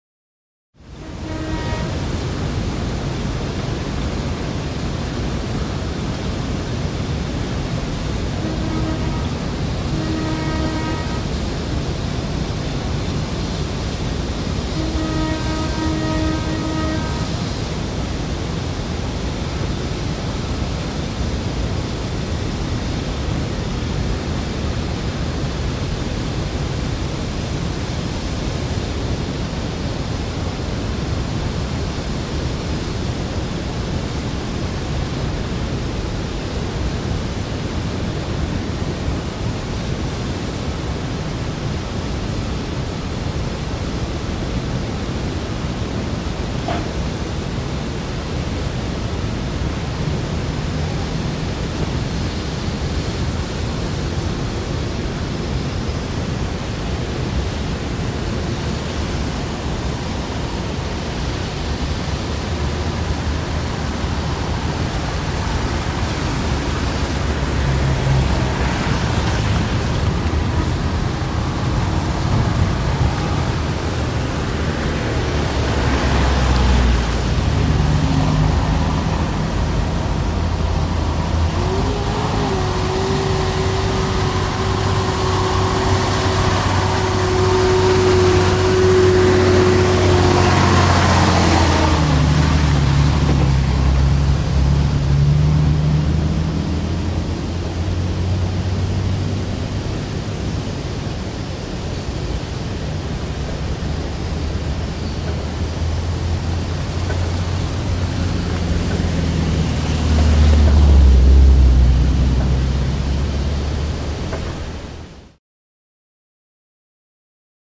Dam Atmosphere; General Atmosphere Exterior Dam During Construction. Heavy Background And Water Roar With Horn In Mid Shot, Then Some Heavy Vehicles Come Up And Past Mic.